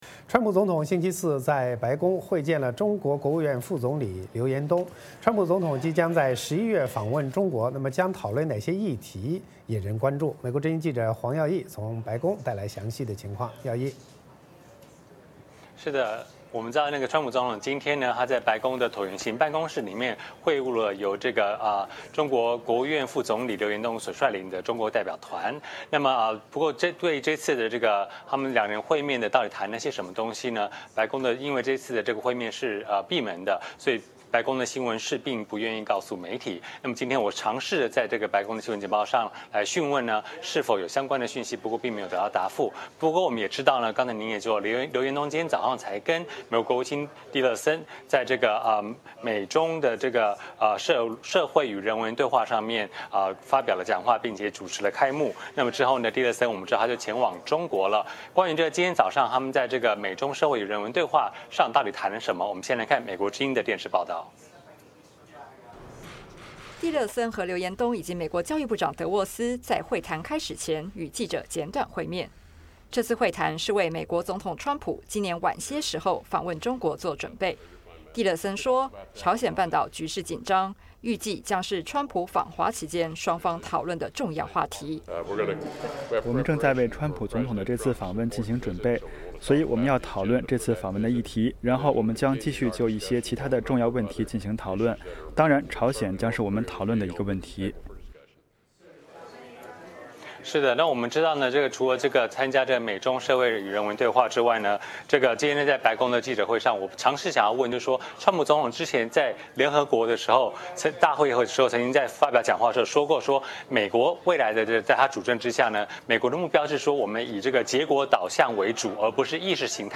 VOA连线：川普在白宫会见中国副总理刘延东